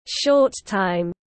Thời gian ngắn tiếng anh gọi là short time, phiên âm tiếng anh đọc là /ˌʃɔːt ˈtaɪm/
Short time /ˌʃɔːt ˈtaɪm/